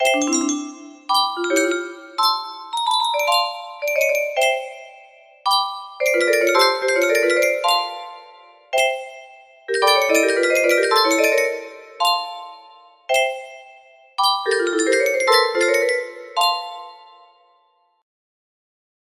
11111 music box melody
Grand Illusions 30 (F scale)